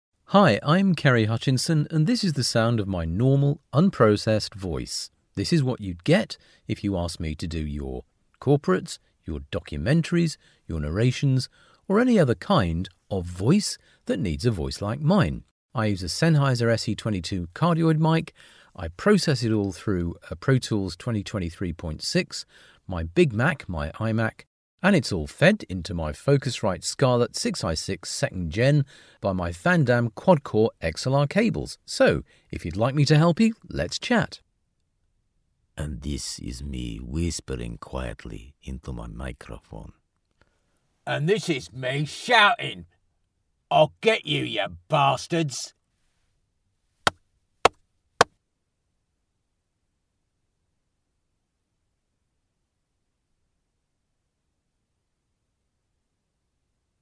Male
Current, versatile, engaging, rich, warm.
Studio Quality Sample
No Processing, + 10 Secs Quiet
Words that describe my voice are Conversational, Natural, Versatile.